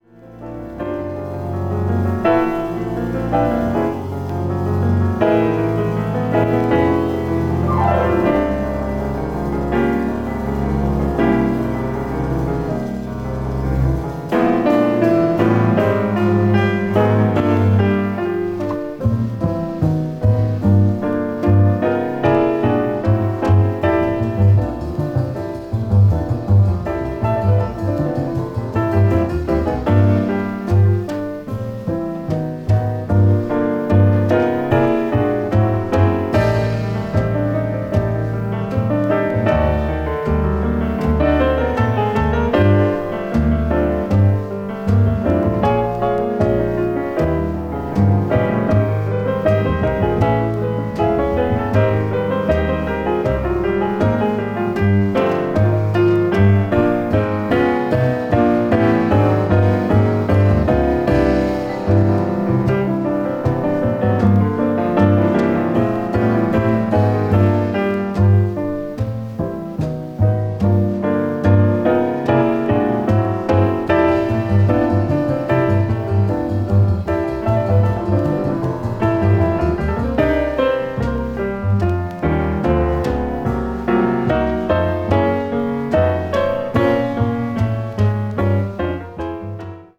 bop   modern jazz   piano trio